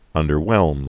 (ŭndər-wĕlm, -hwĕlm)